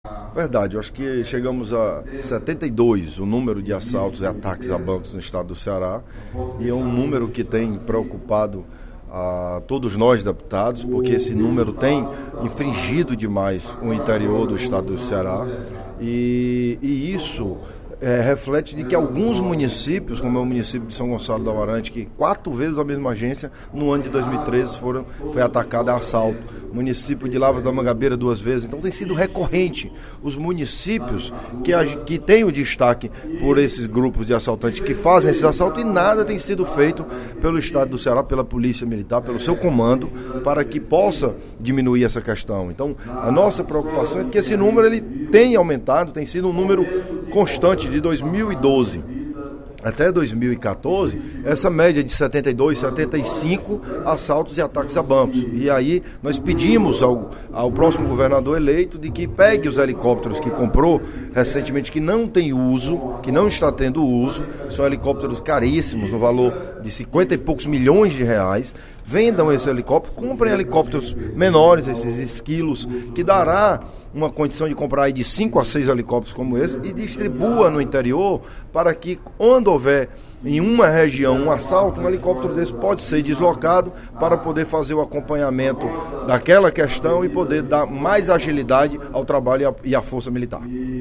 No primeiro expediente da sessão plenária desta sexta-feira (12/12), o deputado Danniel Oliveira (PMDB) mostrou preocupação com os recentes ataques a bancos no Ceará.